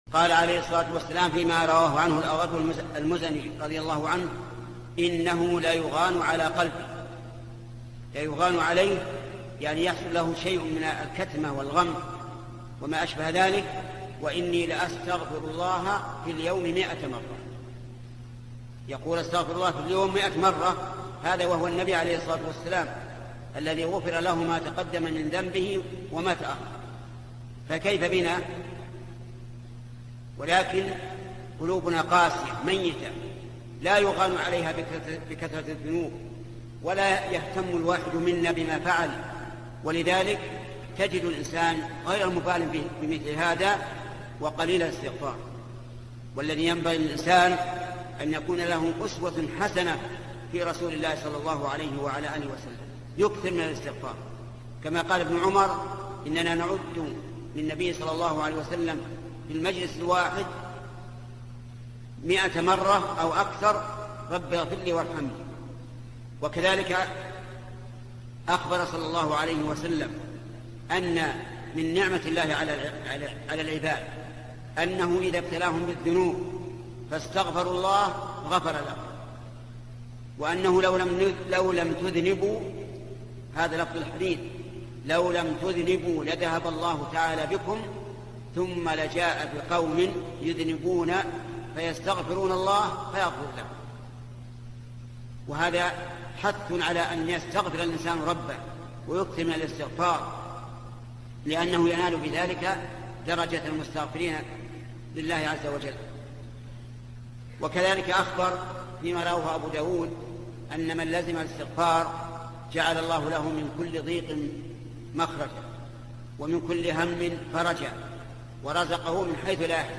مقطع قصير: لزوم الاستغفار - محمد بن صالح العثيمين (صوت - جودة عالية.